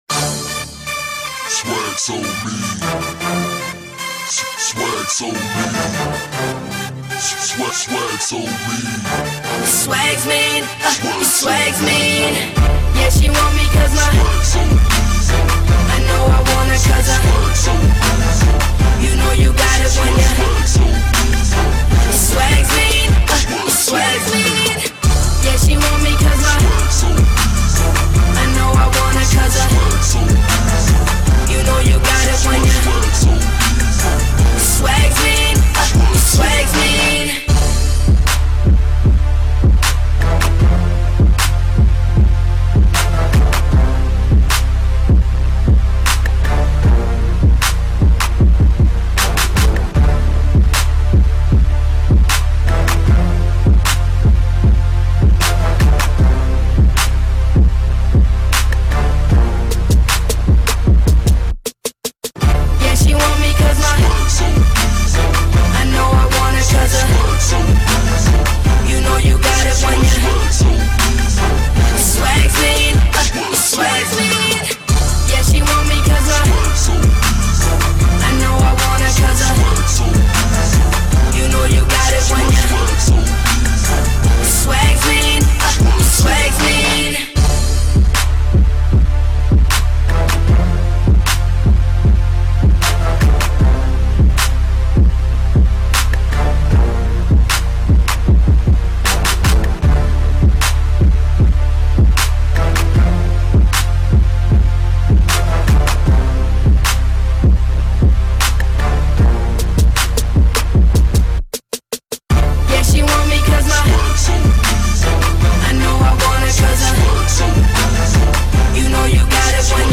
Жанр: R&B, club, HIP - HOP